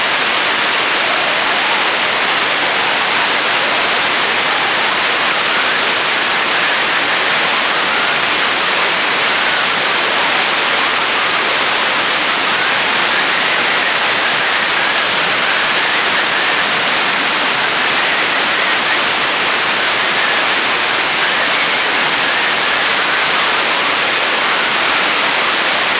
This allowed the use of FSK keyed CW and the use of a narrow band receiver IF.
Frequency drift was still a problem however and with weak signals several repeats of the exchanges were needed to complete the 6.1 and 7.3km QSOs.